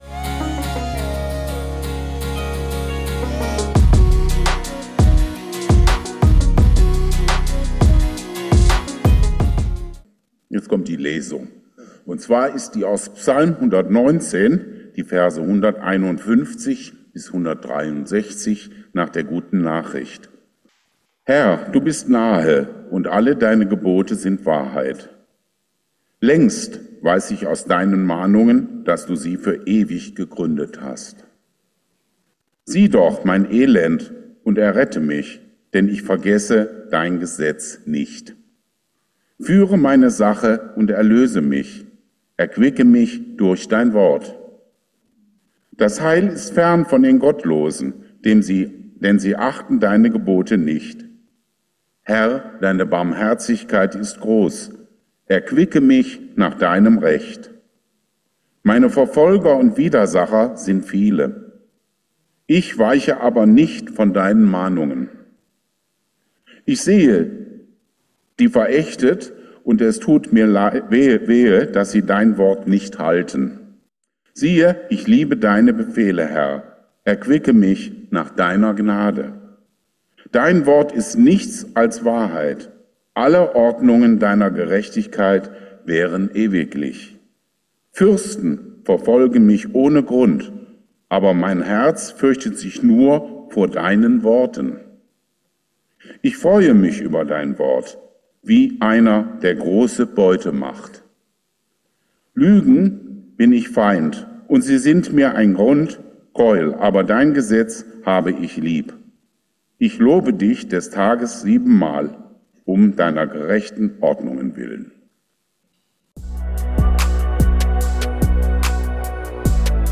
Die Bibel besteht aus altem und neuem Testament und es lohnt sich, sie ganz durchzulesen. Viel Segen beim Hören der Predigt.